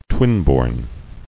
[twin·born]